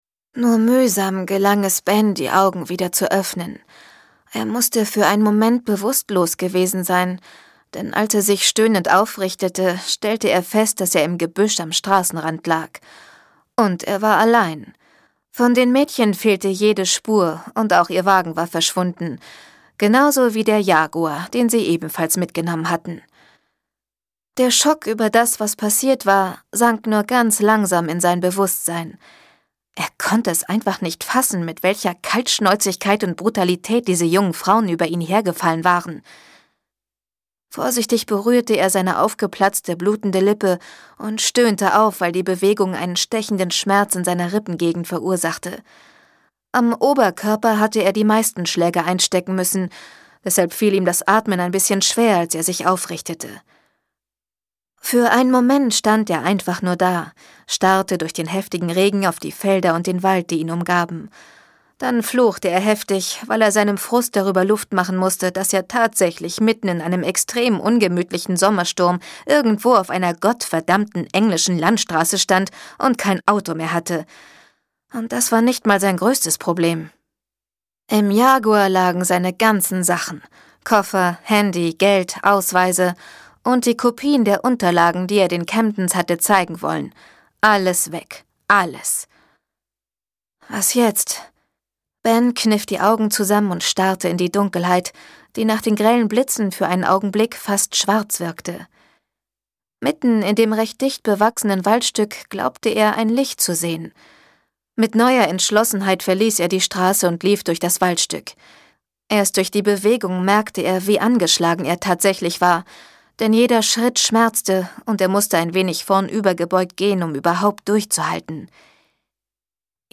Marie Bierstedt (Sprecher)
Marie Bierstedt, Jg. 1974, ist u.a. die deutsche Stimme von Kirsten Dunst, Anne Hathaway und Alyson Hannigan ( Buffy ) und hat bereits den House of Night -Hörbüchern von P.C. Cast ihre Stimme geliehen.